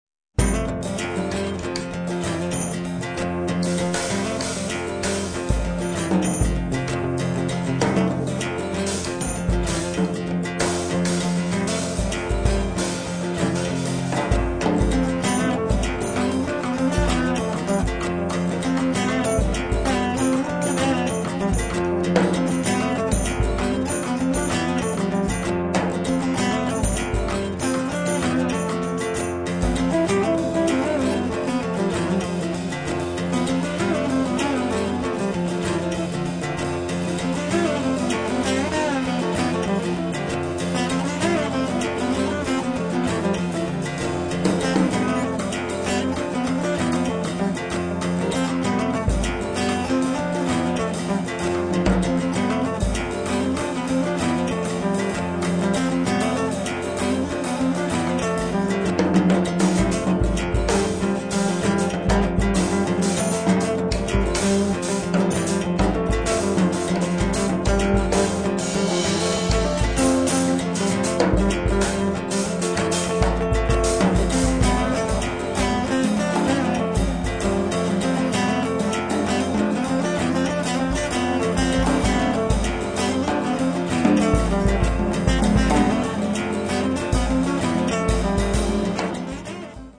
chitarra
batteria e percussioni